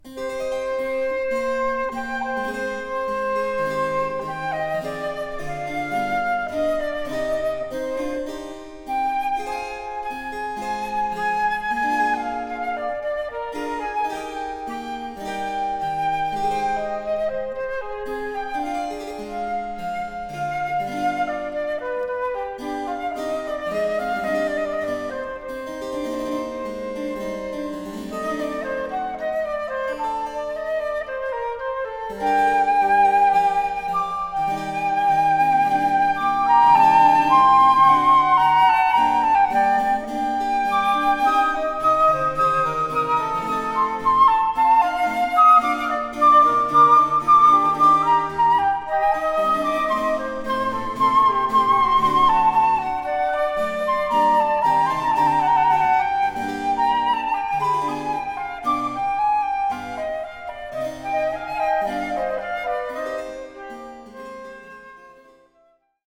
Sonate en trio en re mineur pour 2 flutes et basse continue